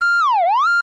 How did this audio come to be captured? Self-recorded using the debug menu